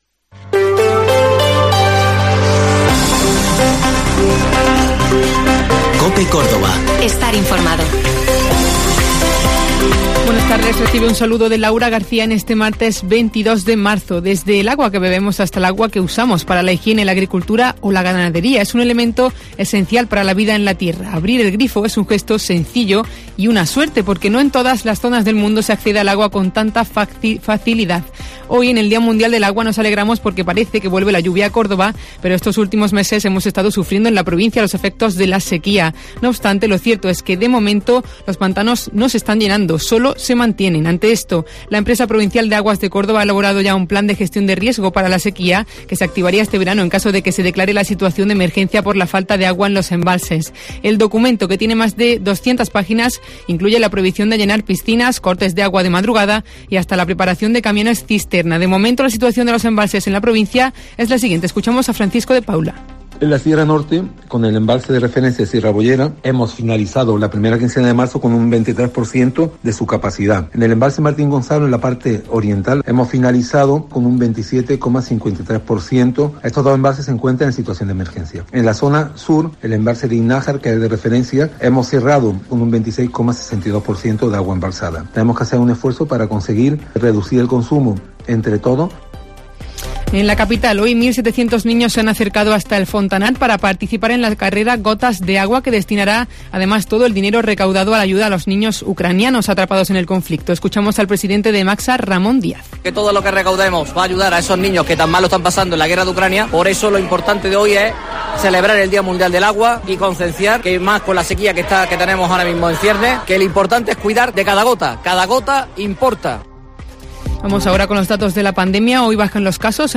Informativo